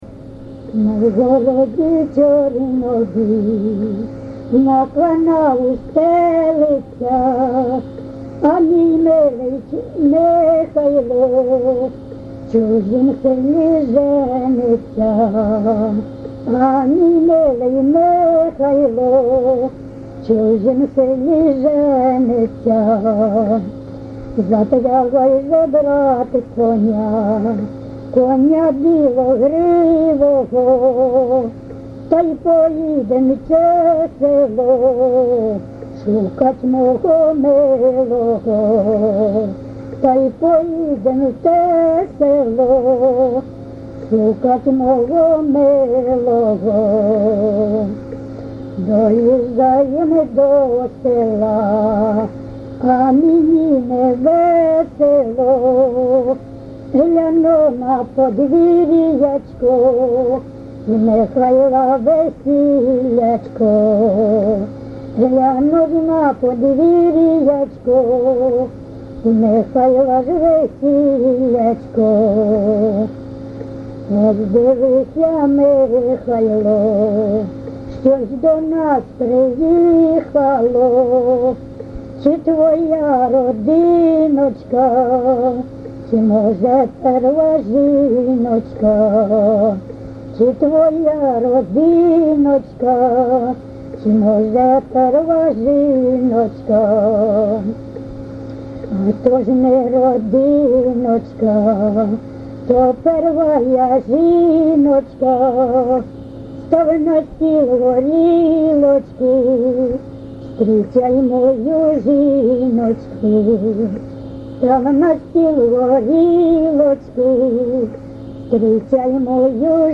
ЖанрПісні з особистого та родинного життя
Місце записус. Коржі, Роменський район, Сумська обл., Україна, Слобожанщина